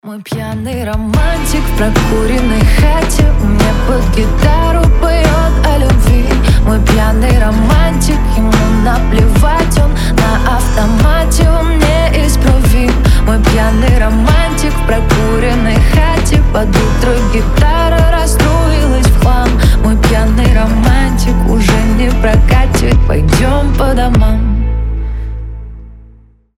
• Качество: 320, Stereo
гитара
лирика
душевные
женский голос